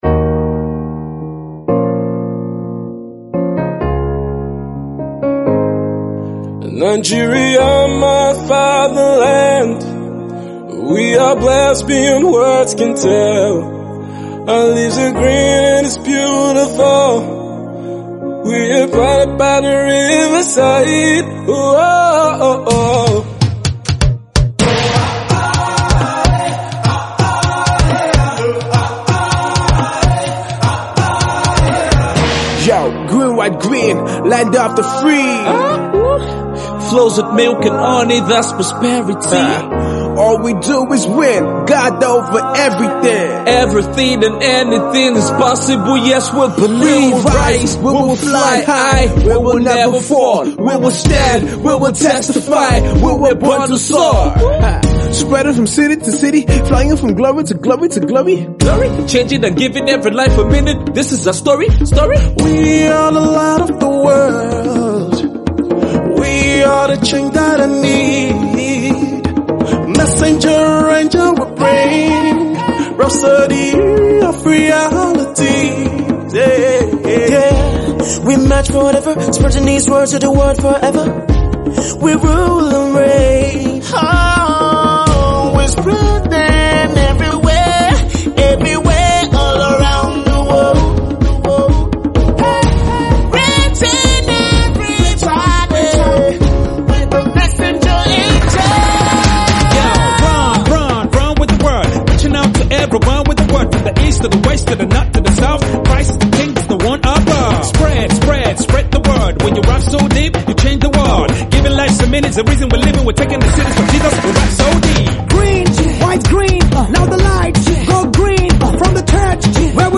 campaign song